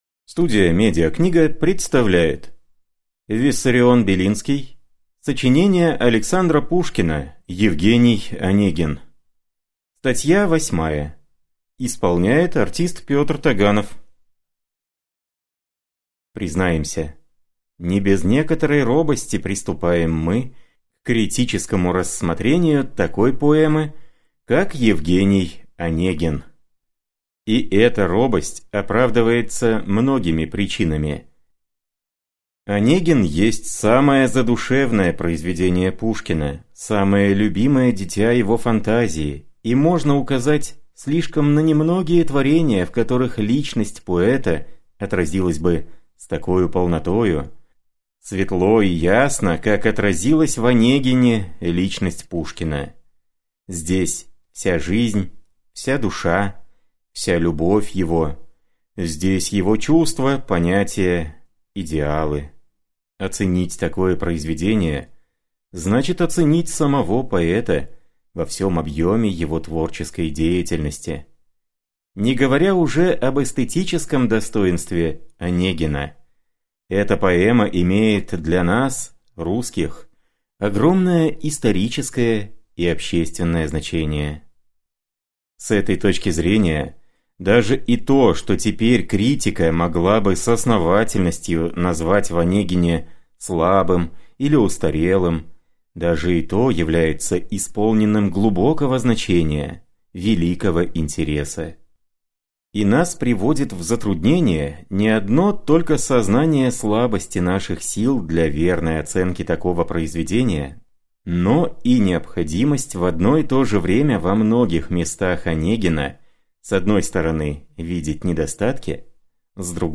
Аудиокнига Сочинения Александра Пушкина: «Евгений Онегин». Статья восьмая | Библиотека аудиокниг